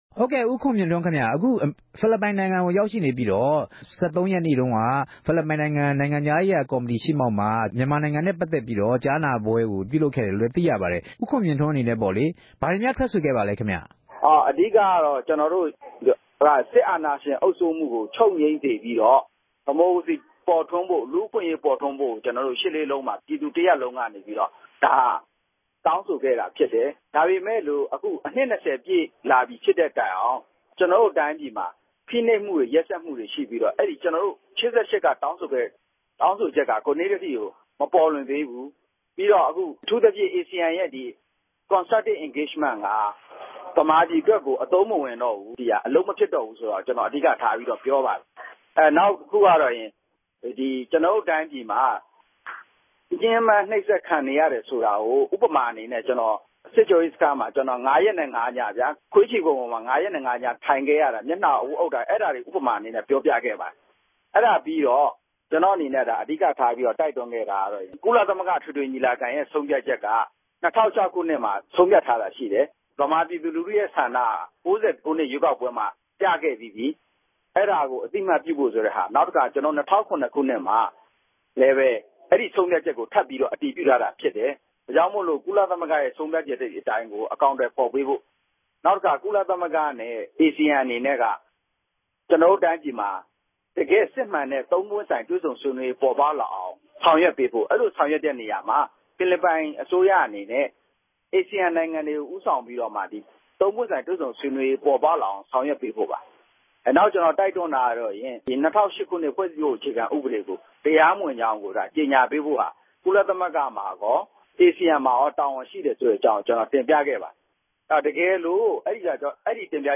ဦးခြန်ူမင့်ထြန်းိံြင့် ဆက်သြယ်မေးူမန်းခဵက်။